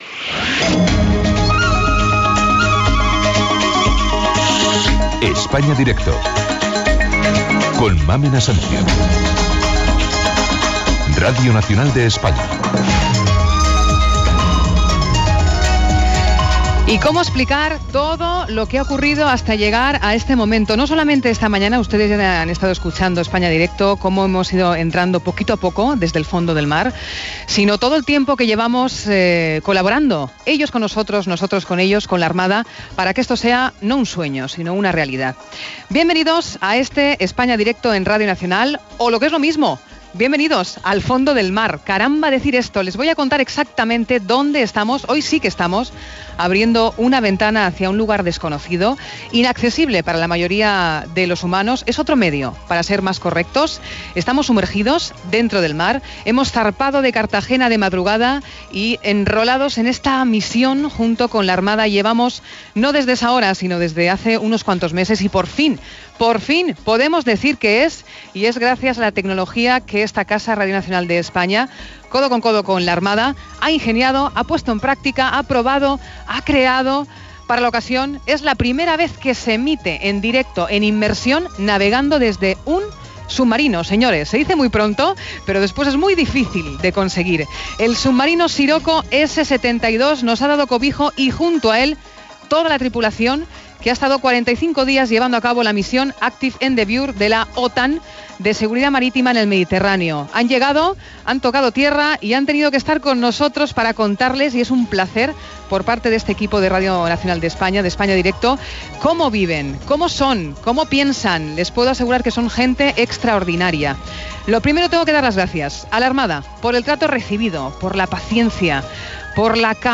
Careta del programa, presentació, entrevistes al comandant capità, al segon comandant i al suboficial més antic.